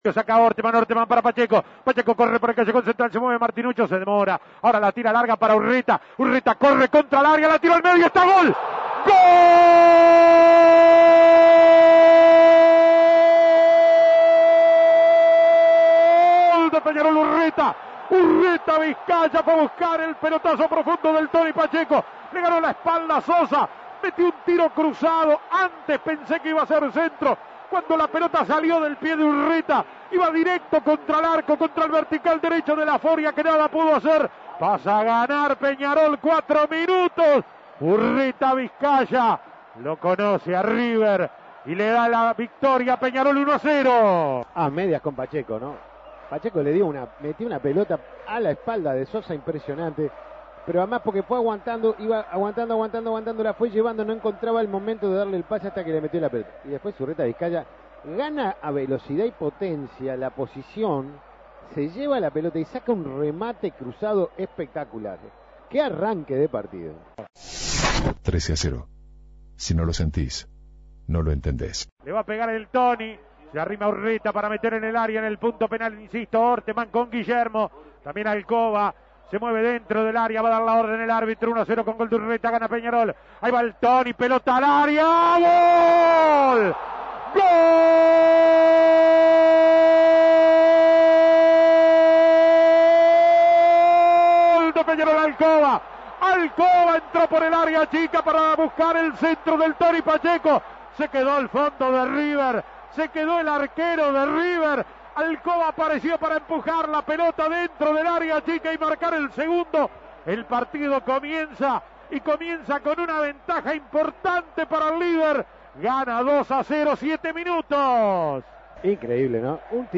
Goles y comentarios